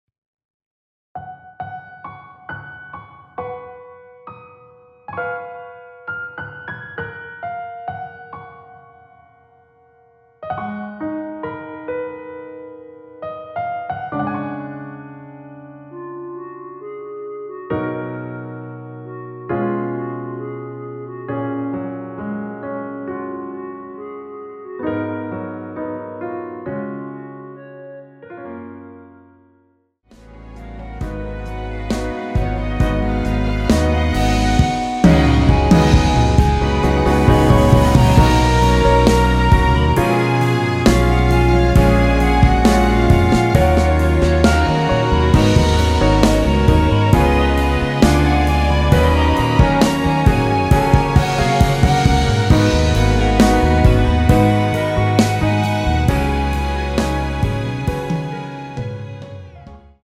원키 멜로디 포함된 MR입니다.
F#
앞부분30초, 뒷부분30초씩 편집해서 올려 드리고 있습니다.
중간에 음이 끈어지고 다시 나오는 이유는